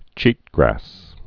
(chētgrăs)